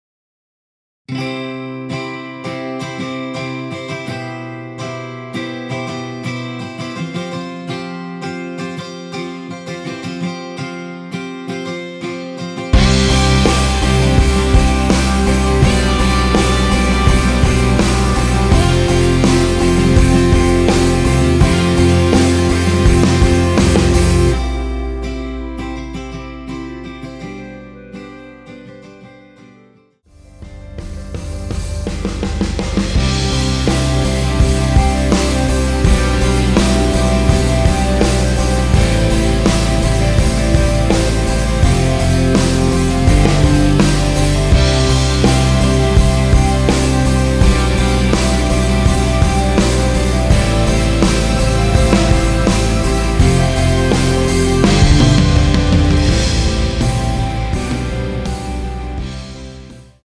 원키 멜로디 포함된 MR입니다.(미리듣기 확인)
앞부분30초, 뒷부분30초씩 편집해서 올려 드리고 있습니다.
중간에 음이 끈어지고 다시 나오는 이유는